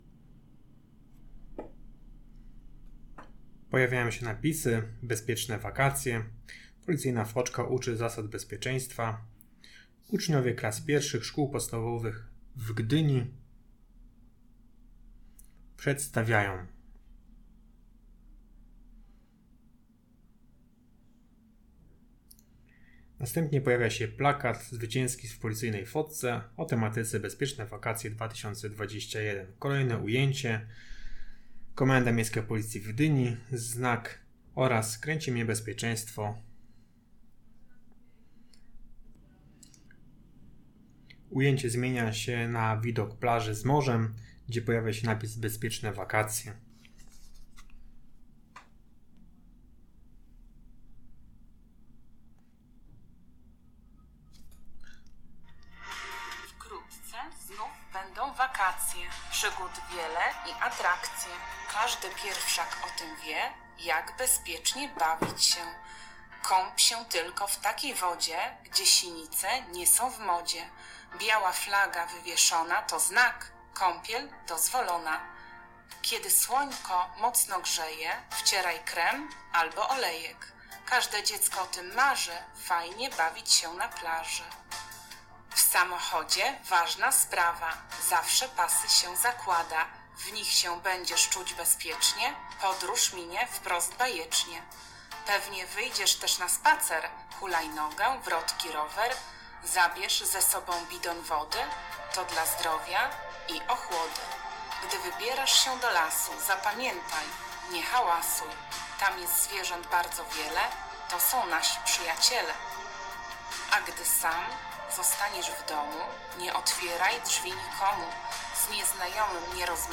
Audiodeskrypcja do filmu "Bezpieczne Wakacje" - plik mp3
Audiodeskrypcja-filmbezpiecznewakacje.mp3